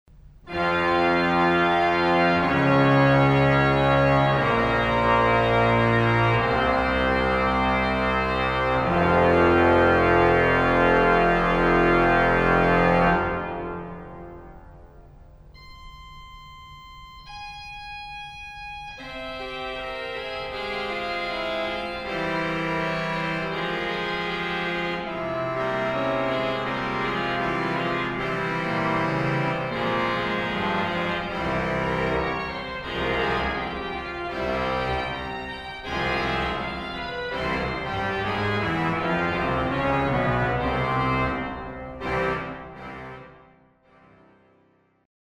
Grand chœur en dialogue ; Récit de Cornet ; quelques épisodes sur la Trompette d’Echo accompagnée au Positif et sur le Nazard du même clavier.
Positif : Trompette, Clairon
G.O. : Cornet, 1ère et 2e Trompette, Clairon
Pédale : Bombarde, Trompette, Clairon